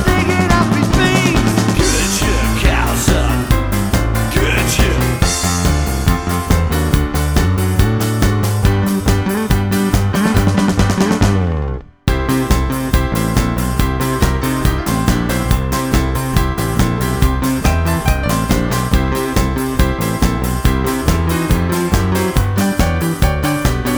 Duet Version Rock 'n' Roll 3:54 Buy £1.50